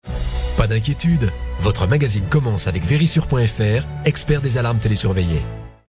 Genre : Vocal